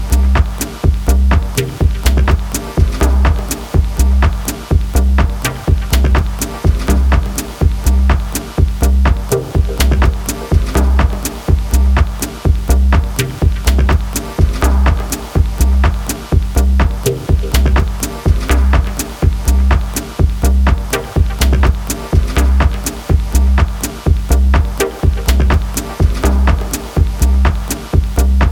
All MPC One and its internal instruments.